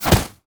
bullet_impact_snow_01.wav